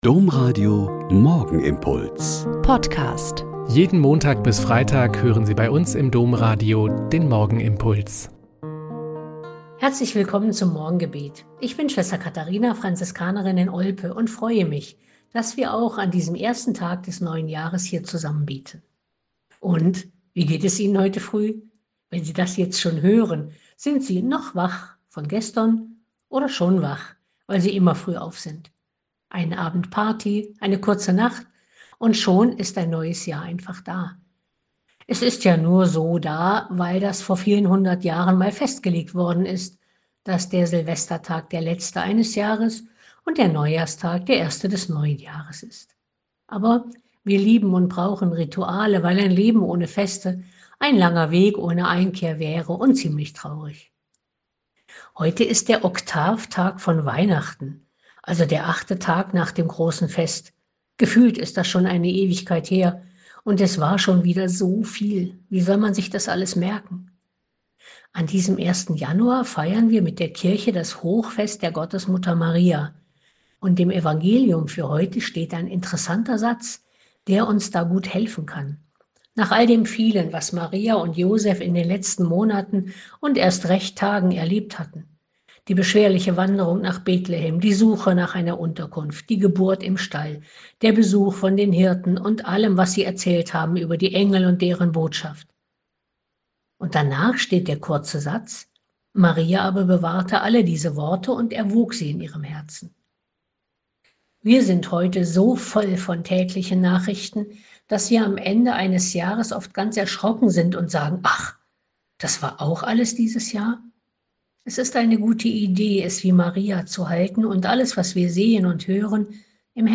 Lk 2,16-21 - Gespräch mit Dr. Tanja Kinkel - 01.01.2025